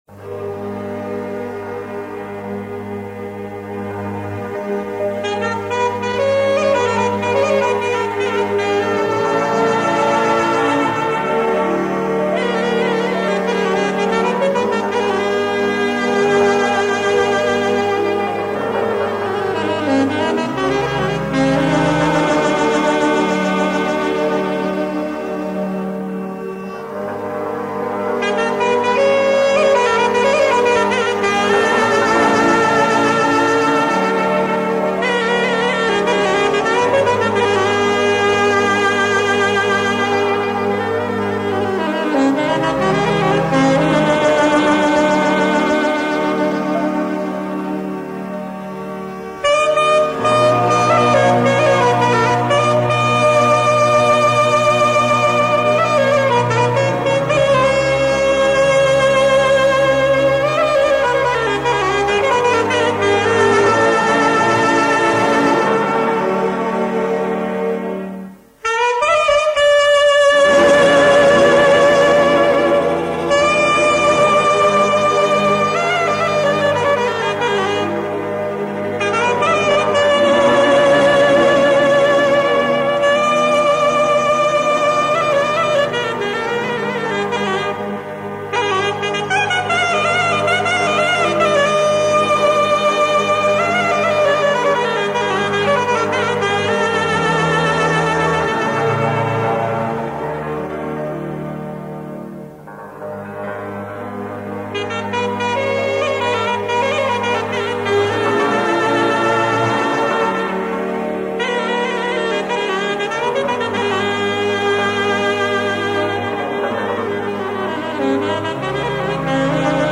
la taragot